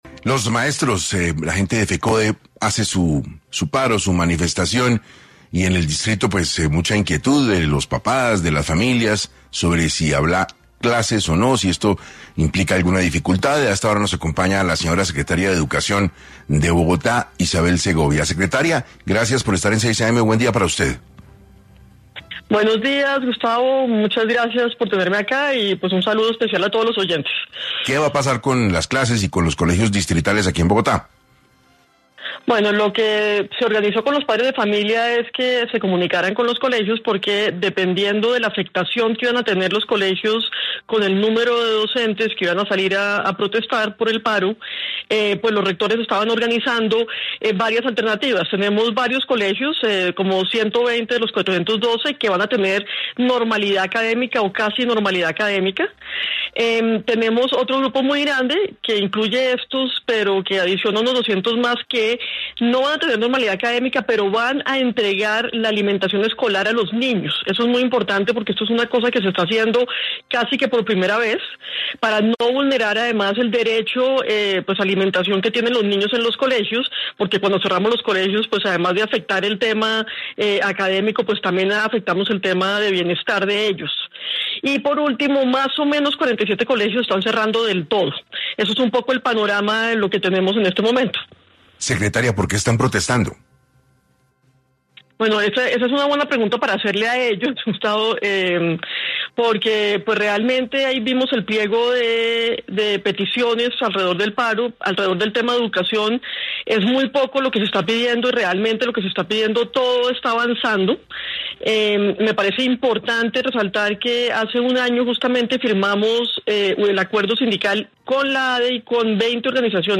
En 6AM de Caracol Radio, la secretaria de educación del distrito, Isabel Segovia habló sobre la situación, las clases en los colegios y la entrega de la alimentación escolar.